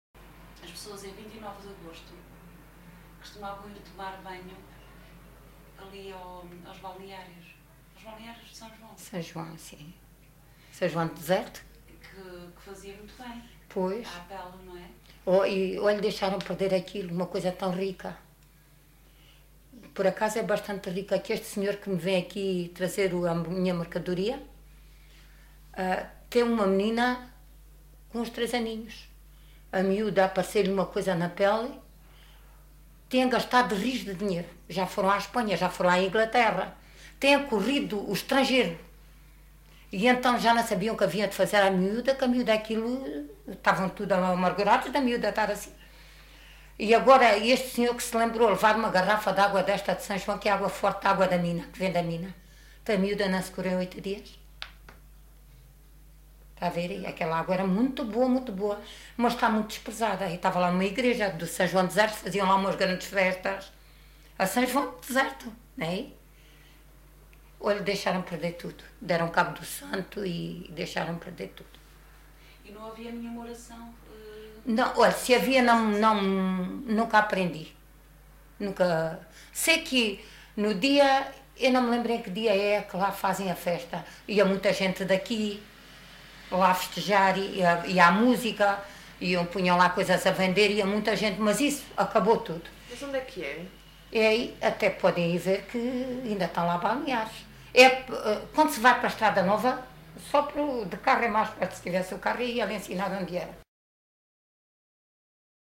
LocalidadeAljustrel (Aljustrel, Beja)